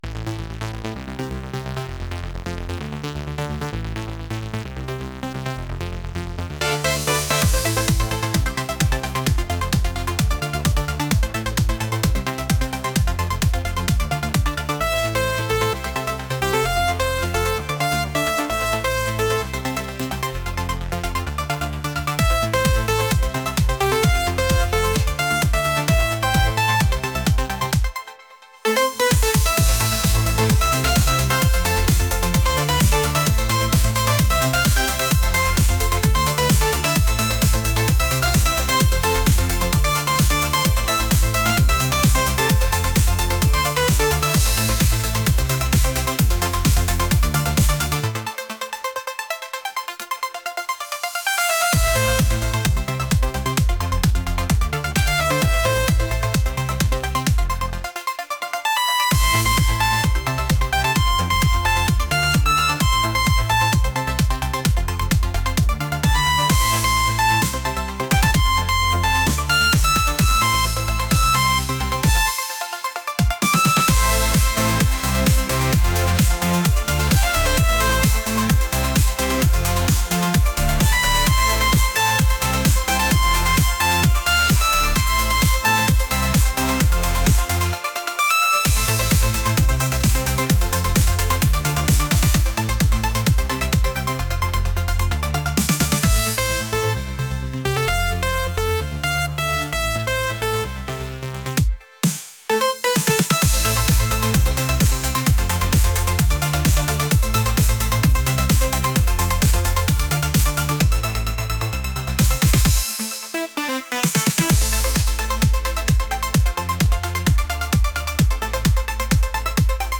electronic | energetic